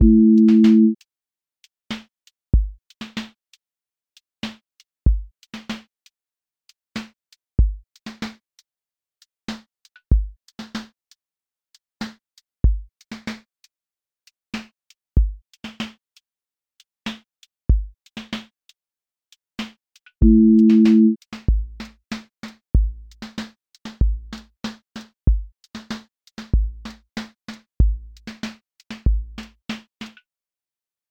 QA Listening Test neo-soul Template: neo_soul_lilt
neo-soul live lilt pocket
• voice_kick_808
• voice_snare_boom_bap
• voice_hat_rimshot
• voice_sub_pulse
• tone_warm_body
• motion_drift_slow